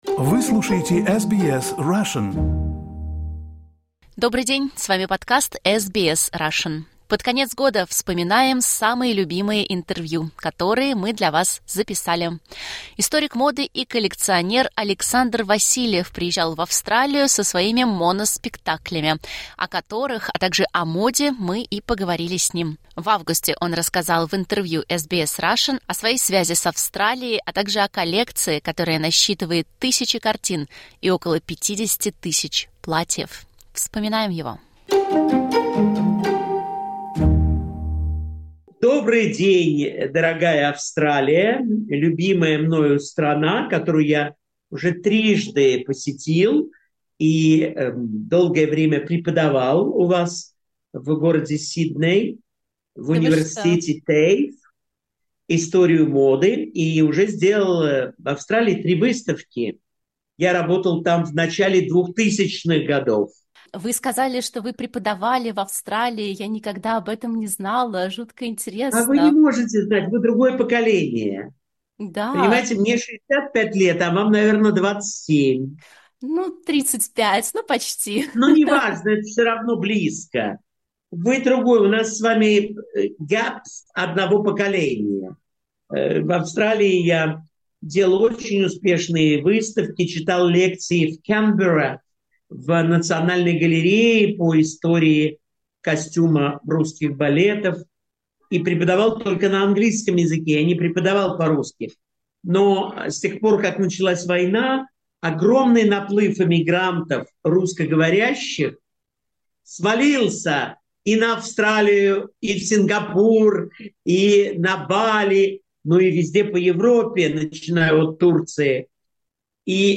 Лучшие интервью 2024. Историк моды Александр Васильев
В августе историк моды и коллекционер Александр Васильев рассказал в интервью SBS Russian о своей связи с Австралией, а также о коллекции, которая насчитывает тысячи картин и около 50 тысяч платьев.